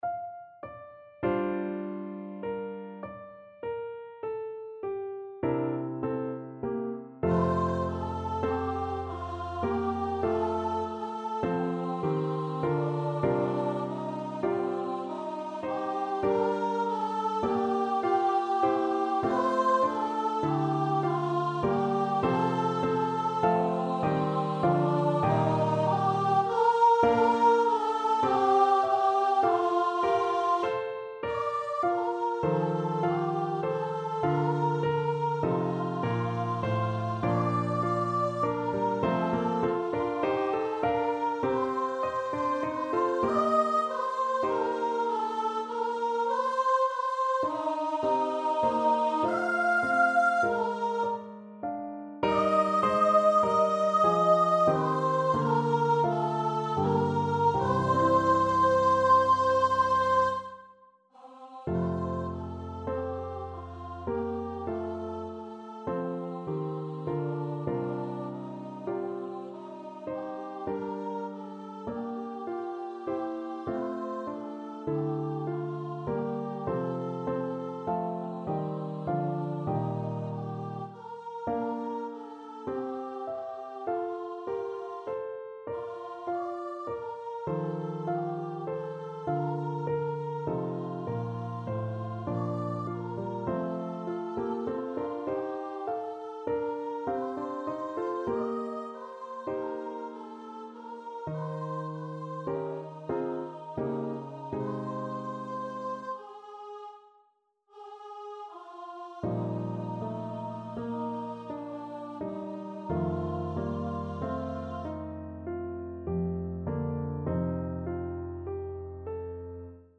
Voice
C5-F6
5/4 (View more 5/4 Music)
F major (Sounding Pitch) (View more F major Music for Voice )
~ = 100 Andante con moto, molto teneramente (View more music marked Andante con moto)
Classical (View more Classical Voice Music)